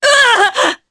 Morrah-Vox_Damage_jp_03.wav